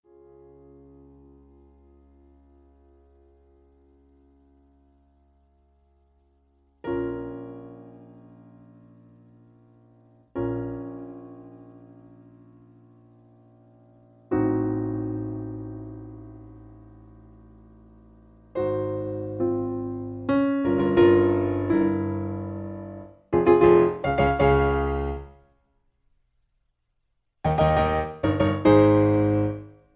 In C sharp. Piano Accompaniment